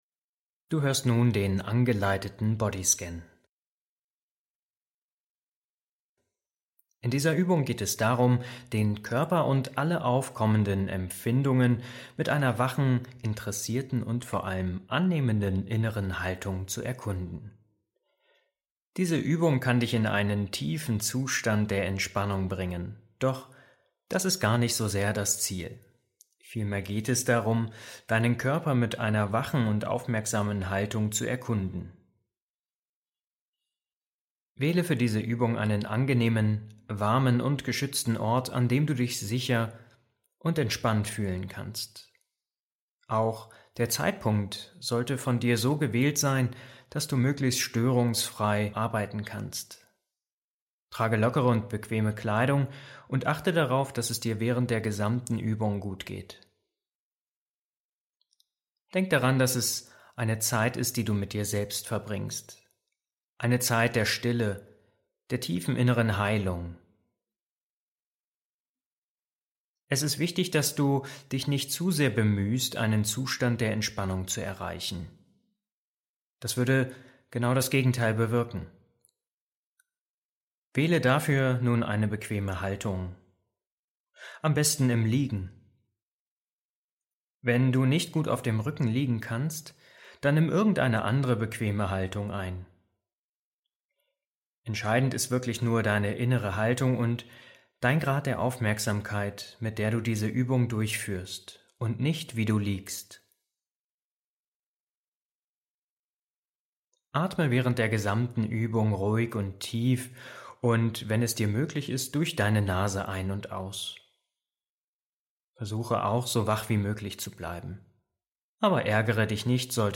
In dieser Folge lade ich dich zu einer geführten MBSR-Bodyscan-Meditation ein. Mit Achtsamkeit und sanfter Aufmerksamkeit nimmst du deinen Körper wahr, entspannst dich tief und findest neue Ruhe.